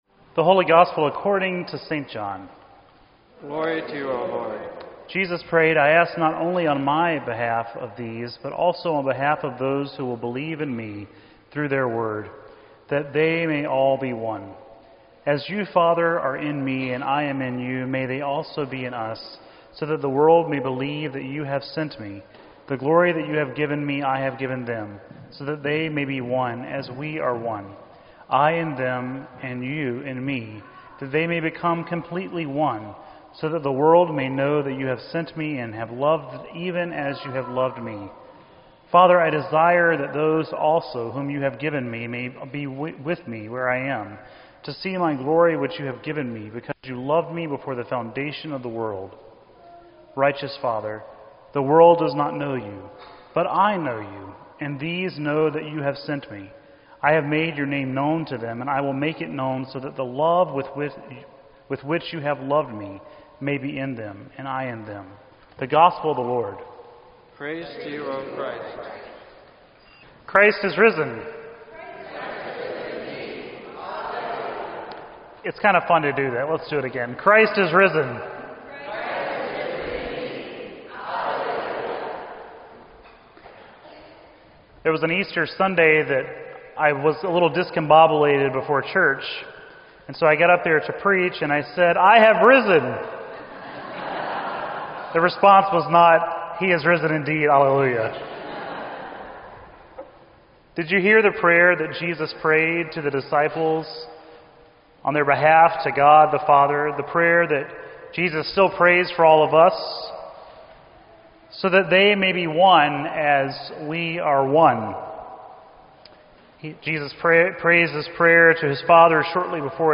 Sermon_5_8_16.mp3